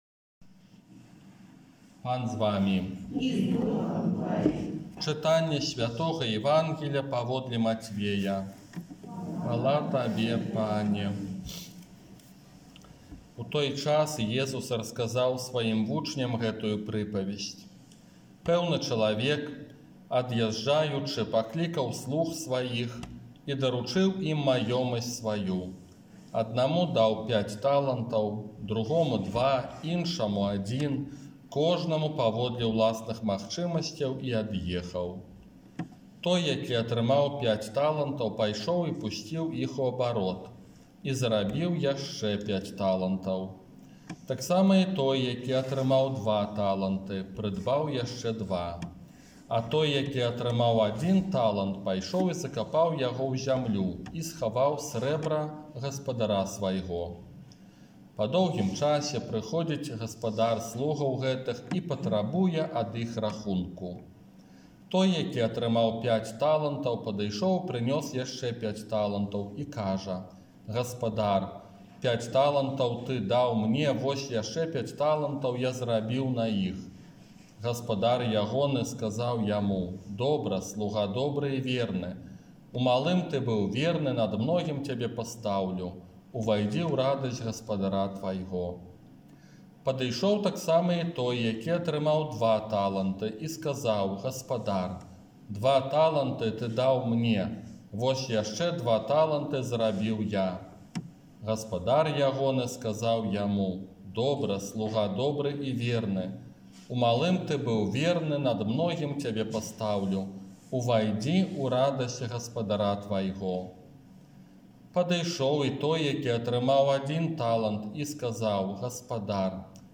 ОРША - ПАРАФІЯ СВЯТОГА ЯЗЭПА
Казанне на трыццаць трэццюю звычайную нядзелю